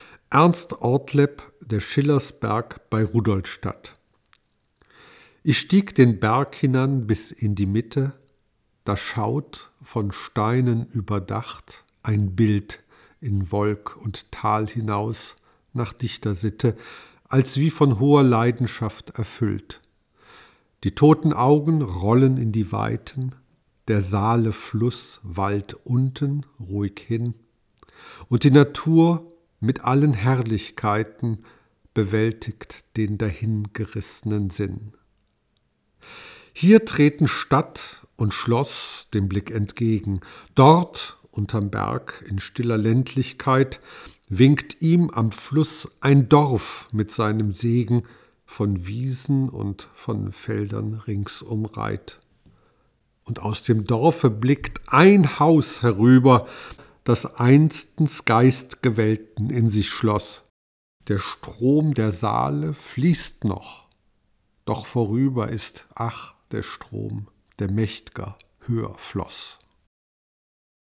Die Audiofassung liest